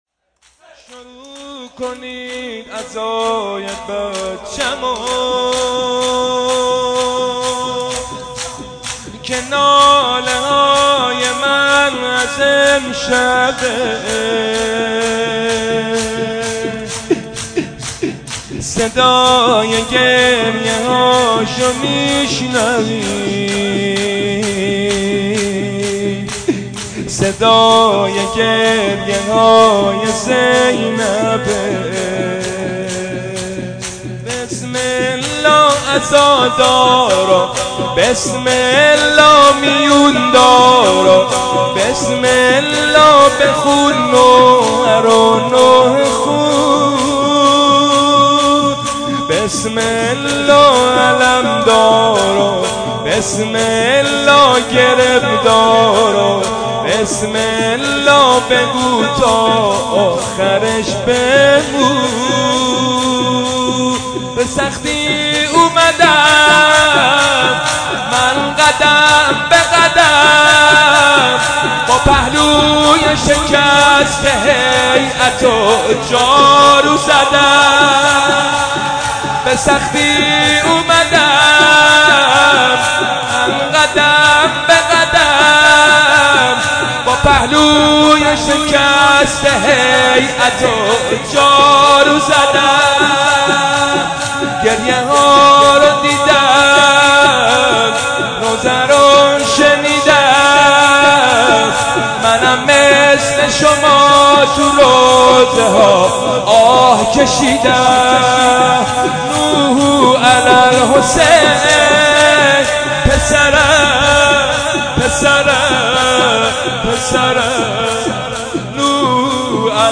مداحی شب اول محرم 1399 با نوای حاج حسین سیب سرخی